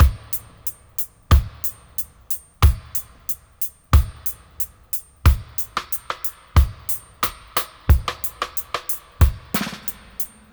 90-FX-02.wav